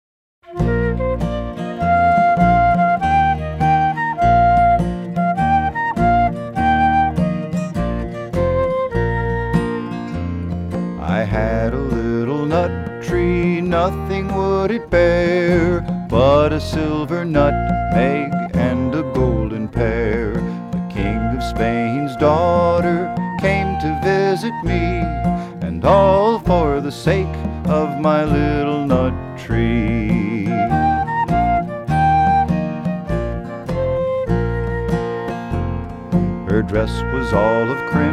Home > Folk Songs
spirited versions of old and new folk songs